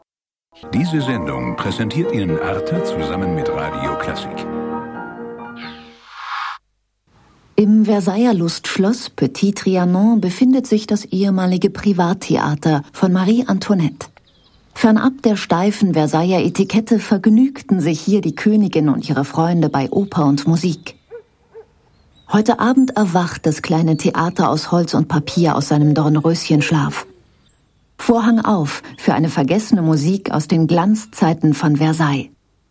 Sprechprobe: Werbung (Muttersprache):
german female voice over artist